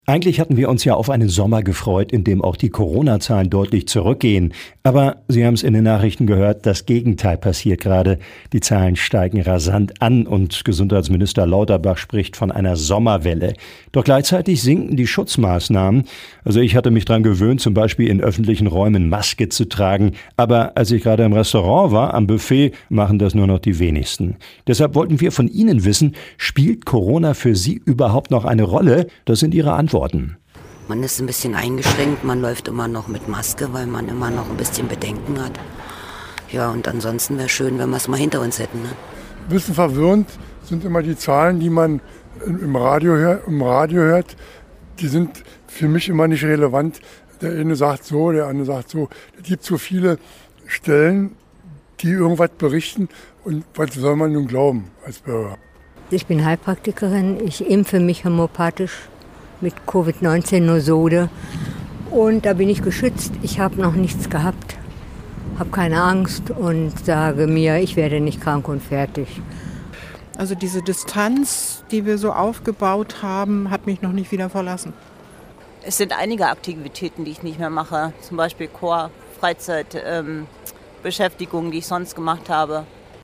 Landkreis Hameln-Pyrmont: UMFRAGE SPIELT CORONA EINE ROLLE – radio aktiv